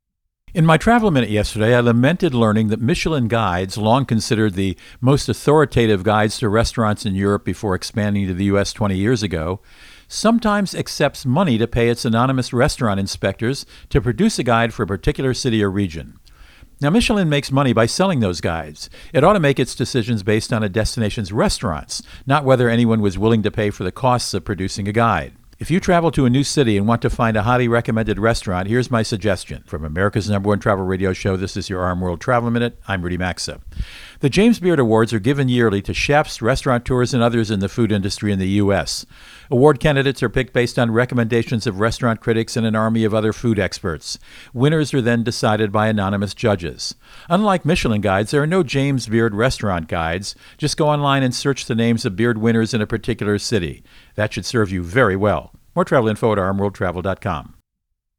America's #1 Travel Radio Show
Co-Host Rudy Maxa | Michelin Guides Alternatives